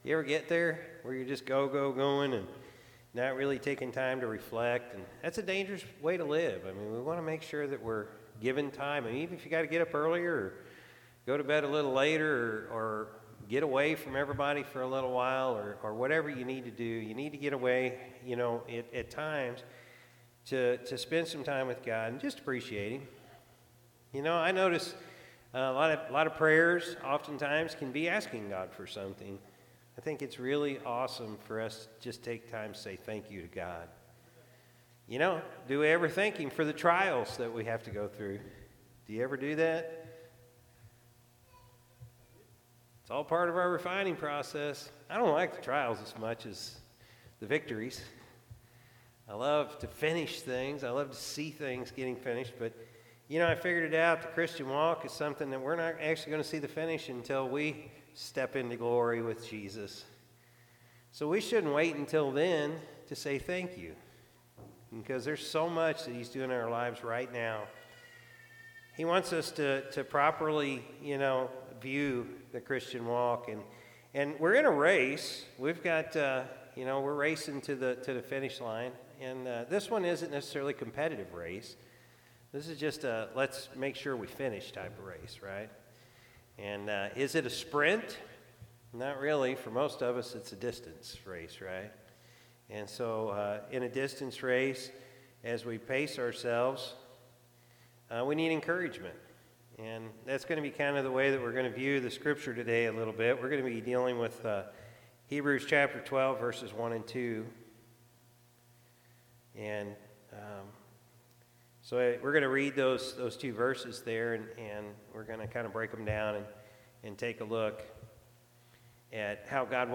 February-4-2024-Morning-Service.mp3